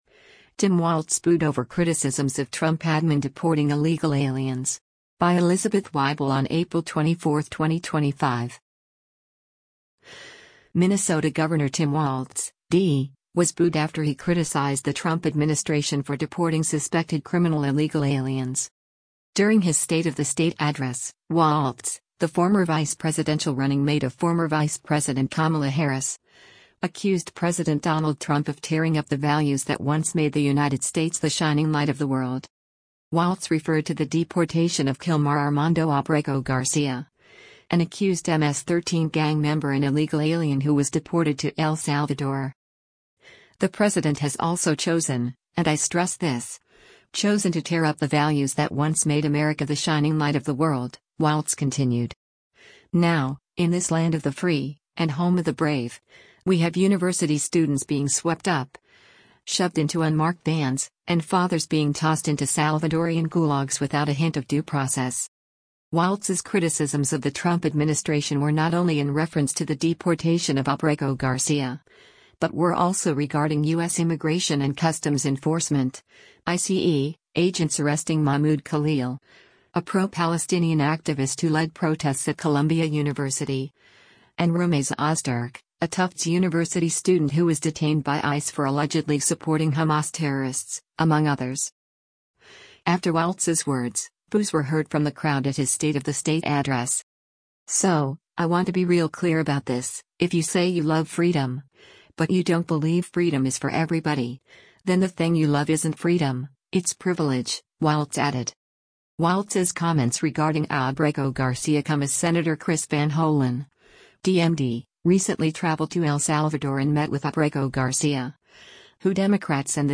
After Walz’s words, boos were heard from the crowd at his State of the State address.